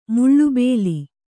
♪ muḷḷu bēli